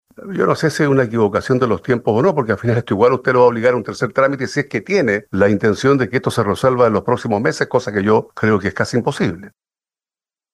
Durante la sesión, parlamentarios consultaron al ministro Cataldo sobre sus reuniones con el Consejo de Rectores de las Universidades Chilenas, particularmente respecto de aquellas instituciones que, si bien están fuera de la gratuidad, participan del CAE, y que tendrían una tasa proyectada de uso del FES del 78% para los quintiles 1, 2 y 3.
Sin embargo, el diputado y presidente de la comisión, Carlos Bianchi, lo increpó, acusándolo de querer extender el debate del proyecto al Senado, donde —según dijo— sería rechazado.